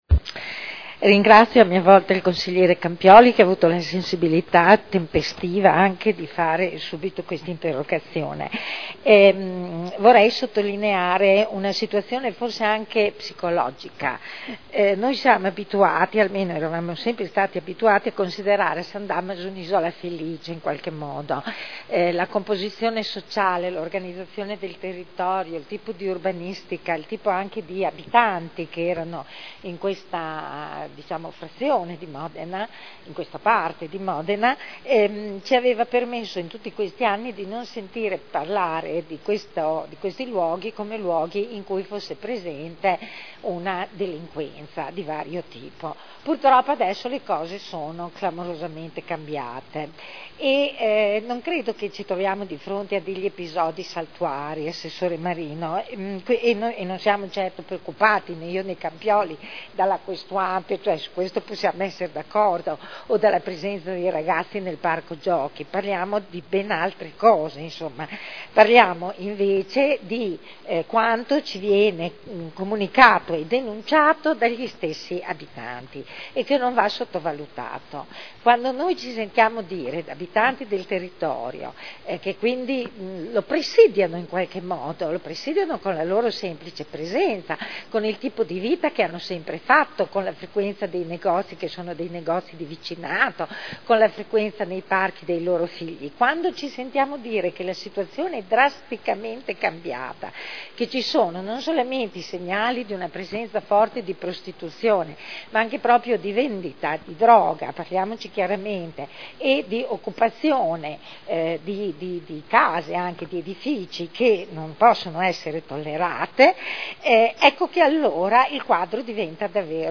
Dibattito.